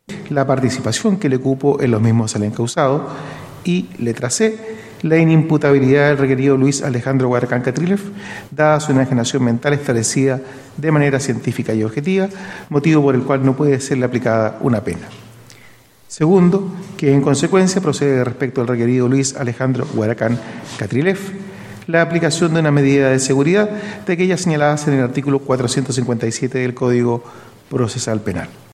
La decisión fue adoptada por la sala integrada por los jueces Wilfred Ziehelmann, Jorge González y su presidente, Roberto Herrera; quien leyó el veredicto.